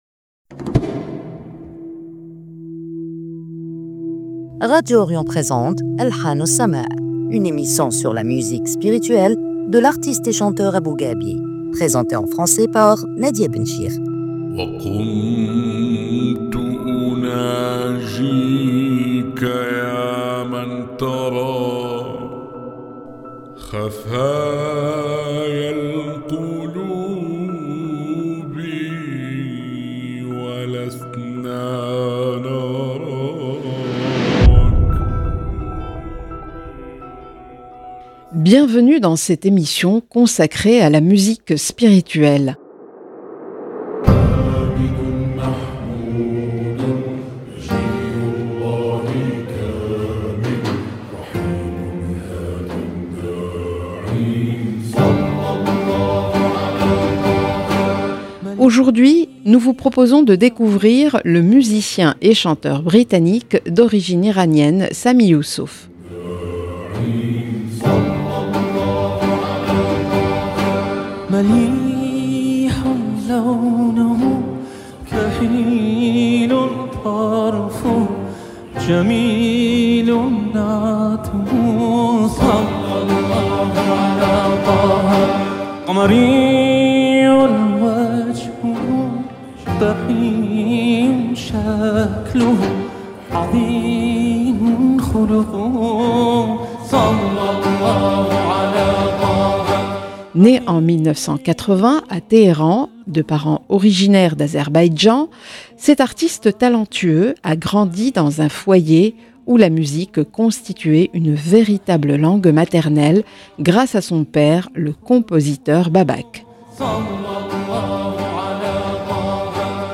une émission sur la musique spirituelle